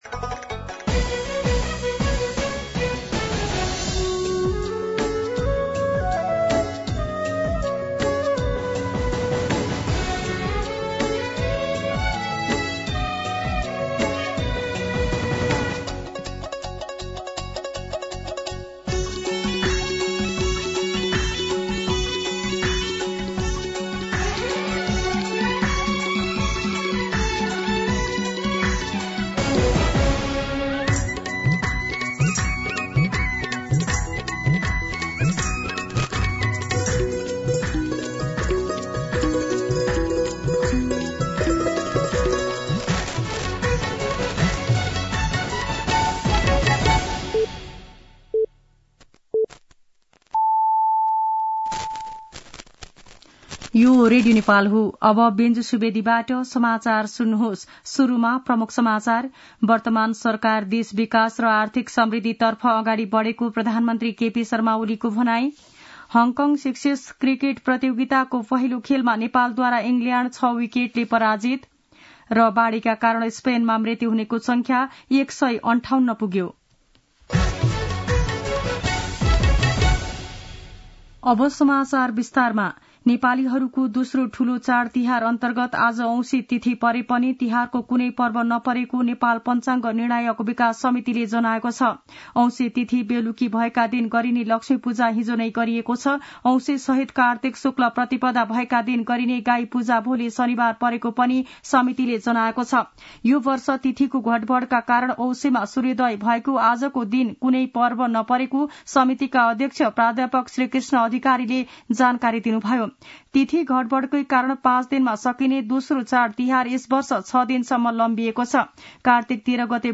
An online outlet of Nepal's national radio broadcaster
दिउँसो ३ बजेको नेपाली समाचार : १७ कार्तिक , २०८१
3-pm-Nepali-News.mp3